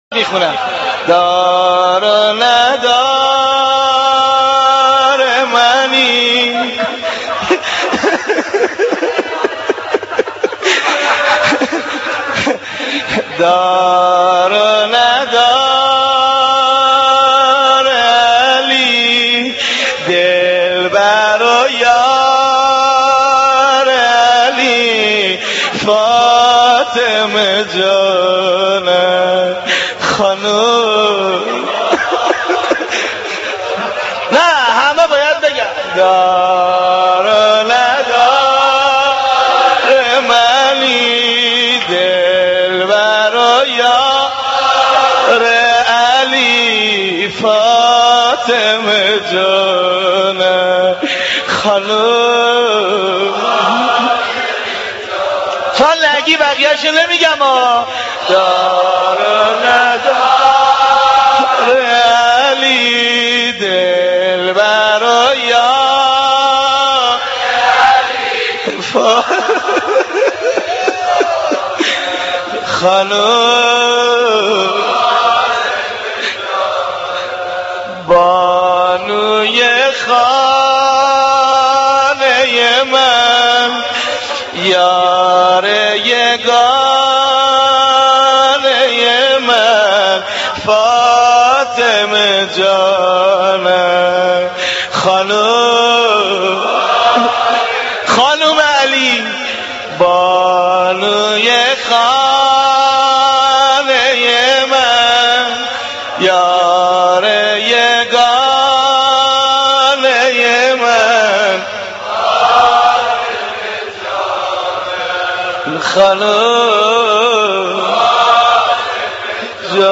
مداحی دلسوز صحبت امام علی(ع